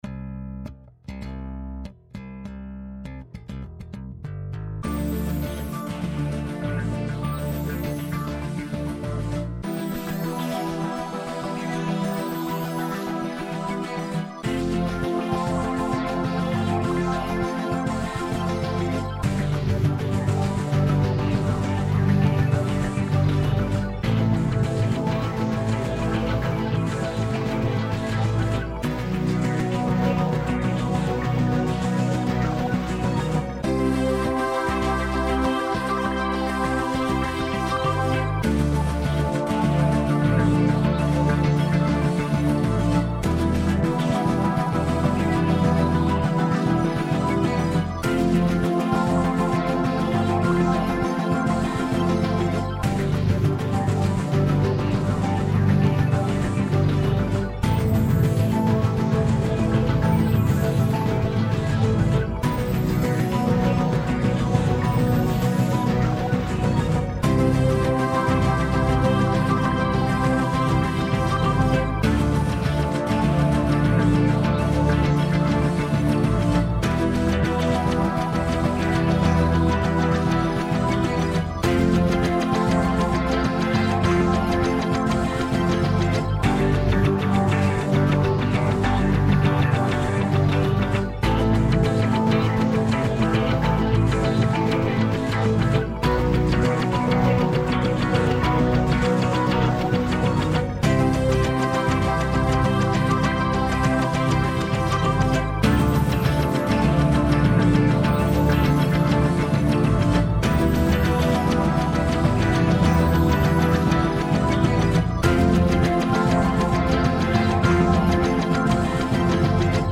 't Is zoiets als een basismuziekje,